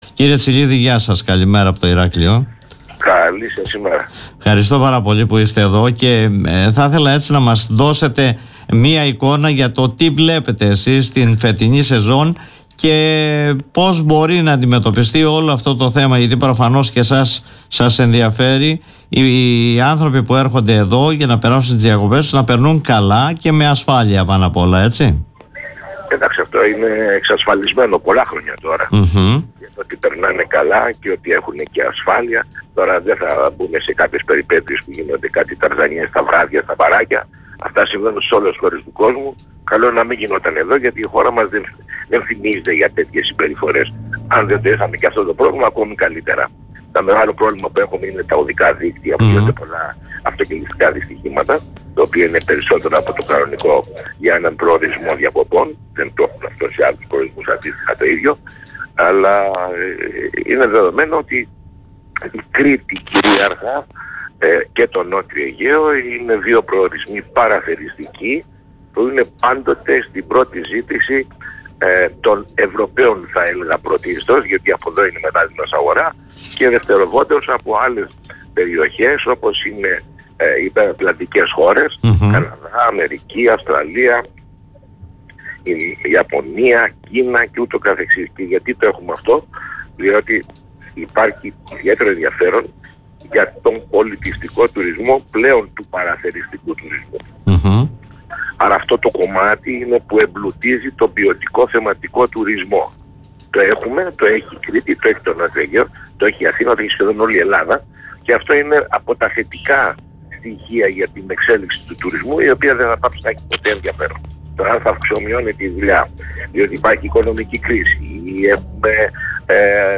που μίλησε στον politica 89.8 και στην εκπομπή “Δημοσίως”